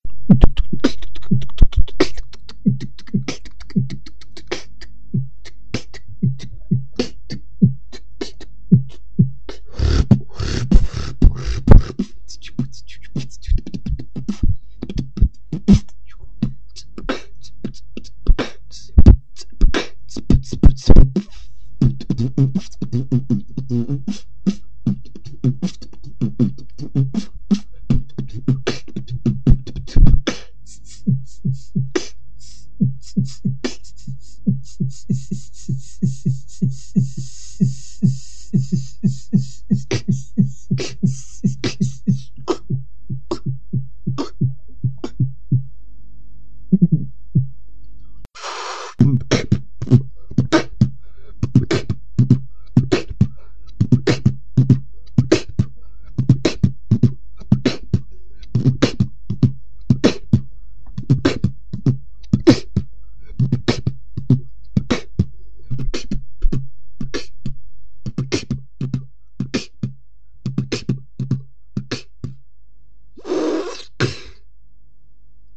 Фристайл
Честно признаюсь, немного обработал, лишнее вырезал и громкости добавил, вообщем слушайте))
Не плохо, но у тебя не большие проблемы с чувством ритма) Попробуй потренировать - вот самое простое упражнение : топни правой нагой , потом хлопок, левой ногой, потом хлопок, потом все заного. сначало делай медлено, потом ускоряйся, это только кажется легко, но на самом деле с первого раза сделать быстро не получалось ни у кого))